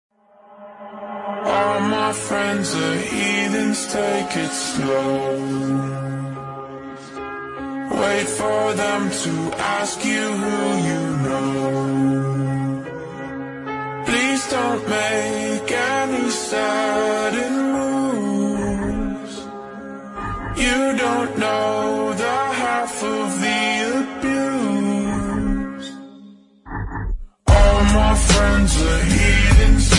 Reggae
Rock